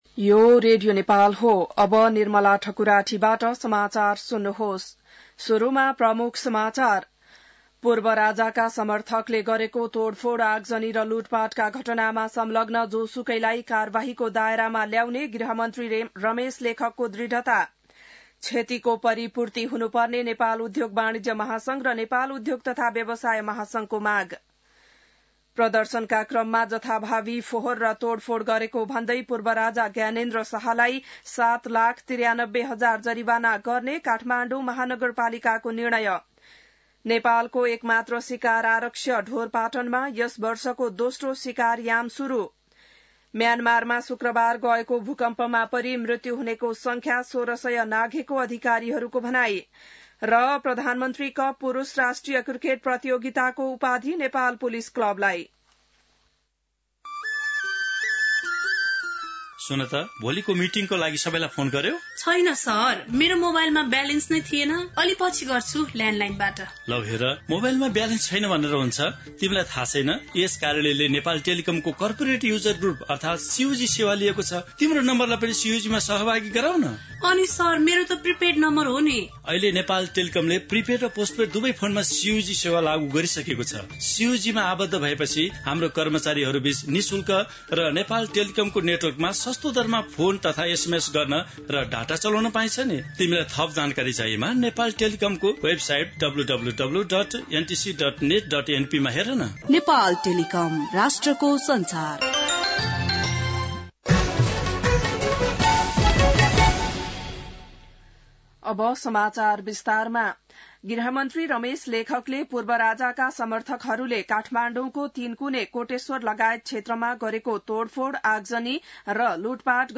बिहान ७ बजेको नेपाली समाचार : १७ चैत , २०८१